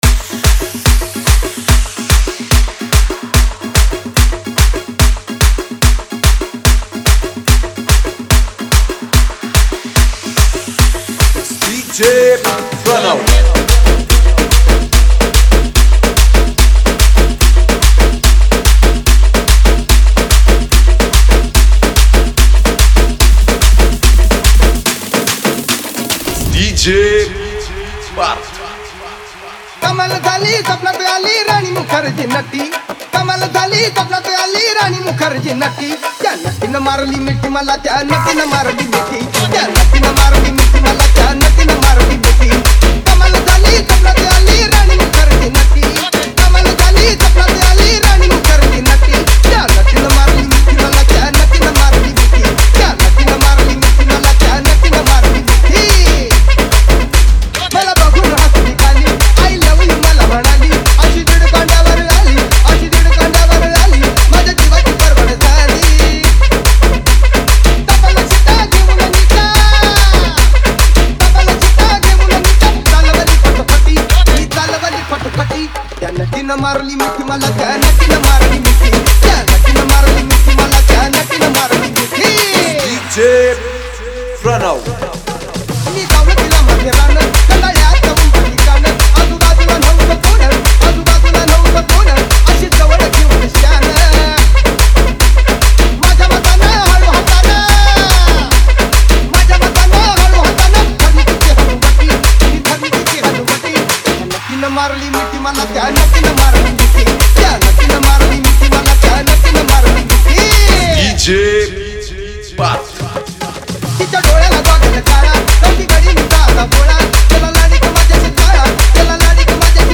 • Category: MARATHI SINGLE